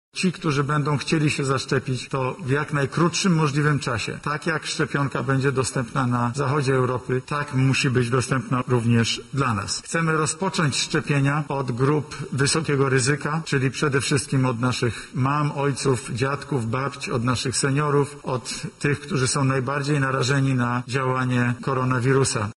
O tym, kto pierwszy otrzyma szczepionkę mówi premier Mateusz Morawiecki